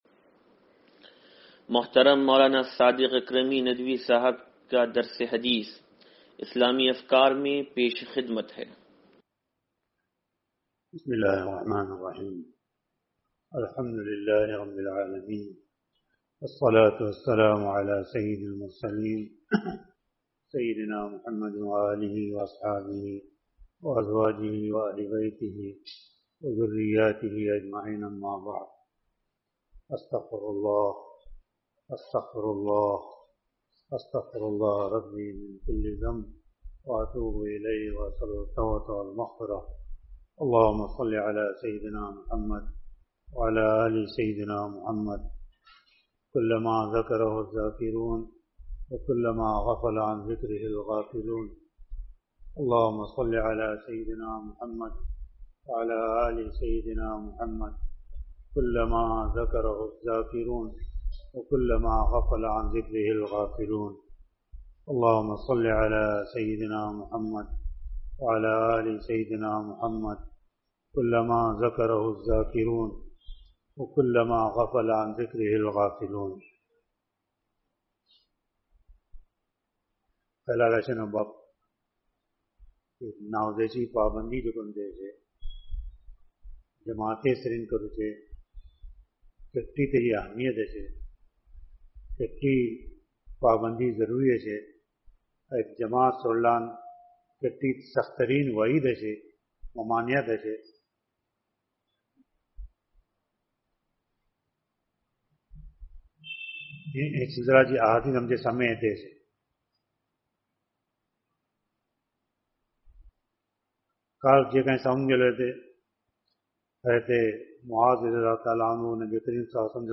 درس حدیث نمبر 0819